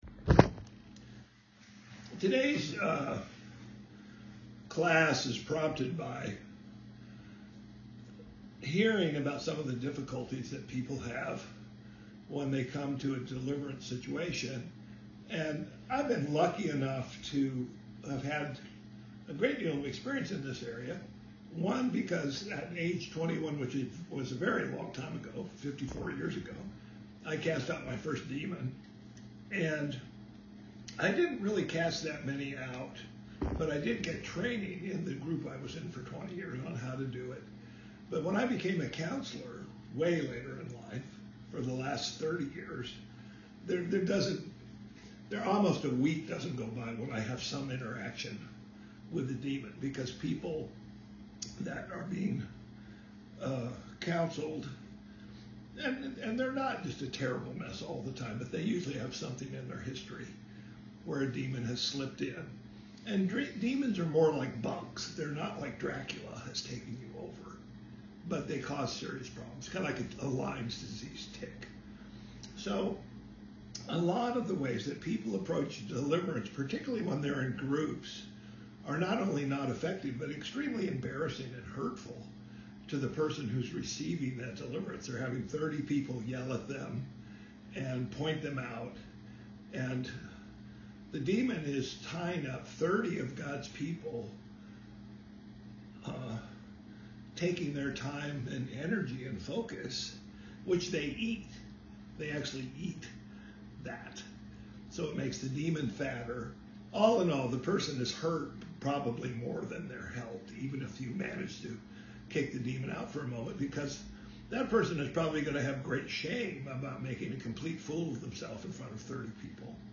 Today’s class is prompted by hearing about some of the difficulties people have when they come into a deliverance situation.